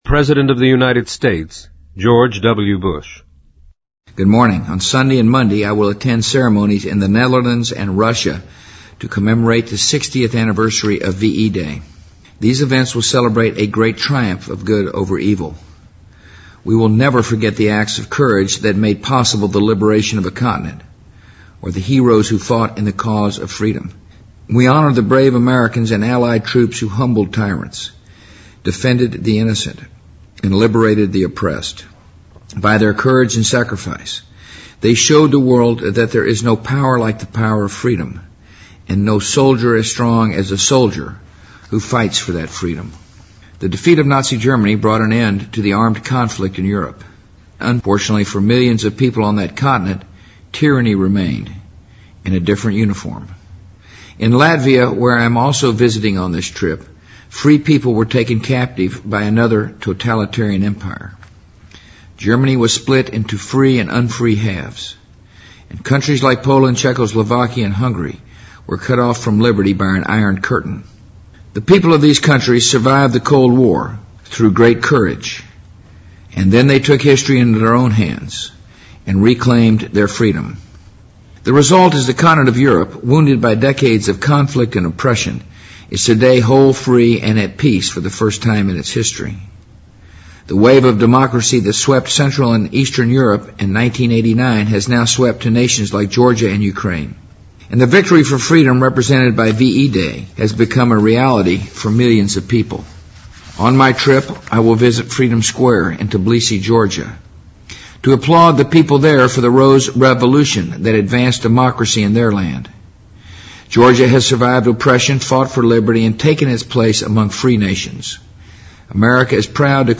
【美国总统电台演说】2005-05-07 听力文件下载—在线英语听力室